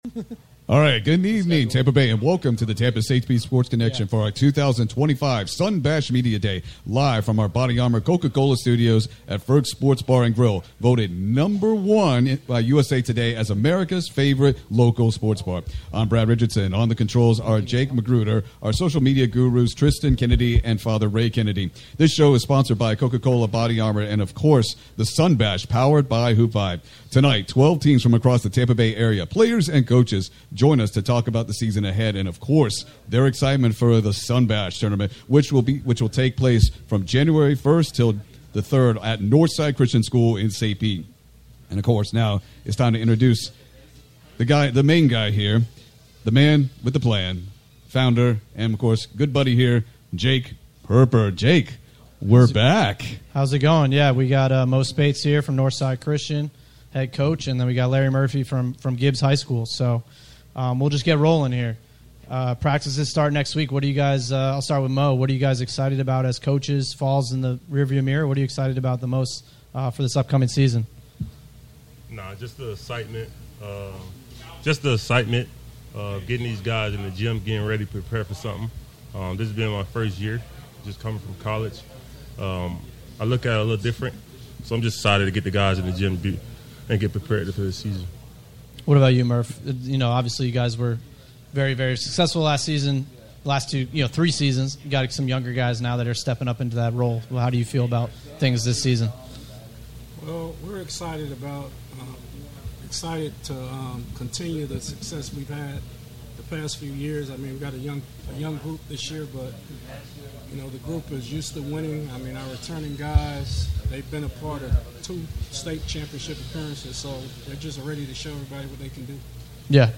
"Sun Bash Media Day" Live from Ferg's on the Florida Sports Stream Channel 10-21-25